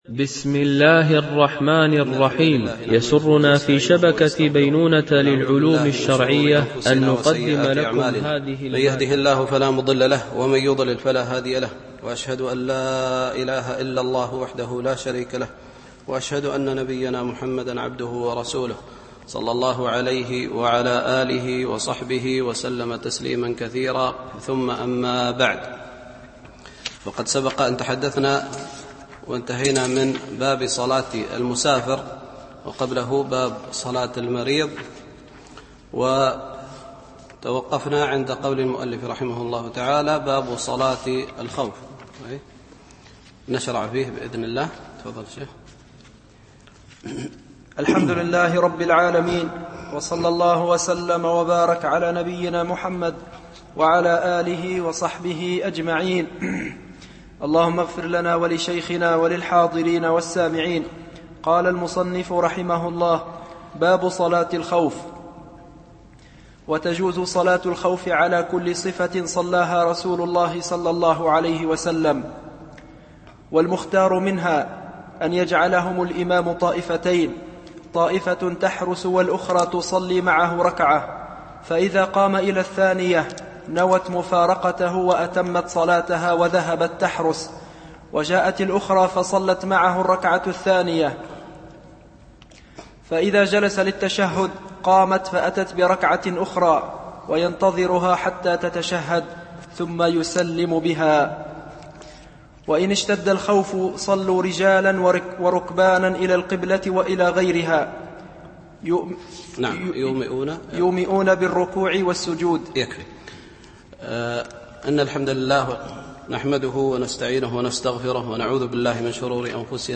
شرح عمدة الفقه ـ الدرس 38